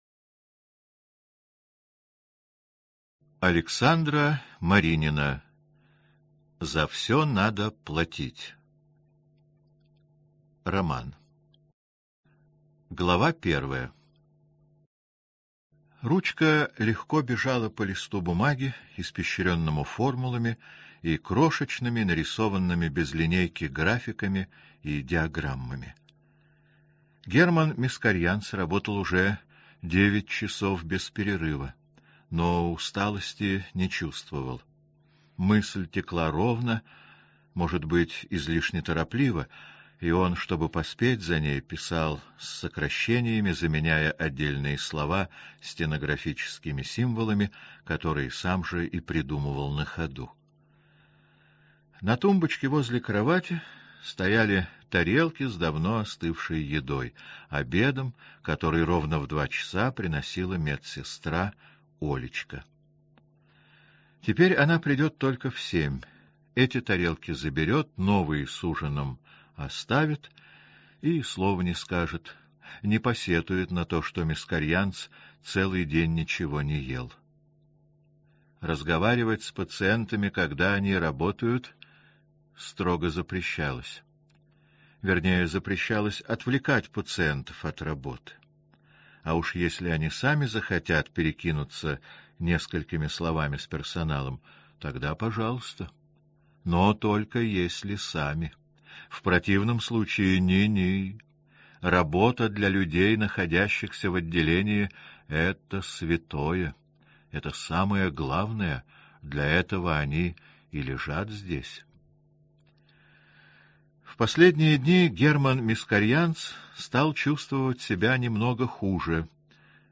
Аудиокнига За все надо платить. Часть 1 | Библиотека аудиокниг